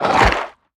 Sfx_creature_penguin_catch_fish_01.ogg